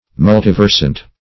Search Result for " multiversant" : The Collaborative International Dictionary of English v.0.48: Multiversant \Mul*tiv"er*sant\, a. [Multi- + L. versans, p. pr.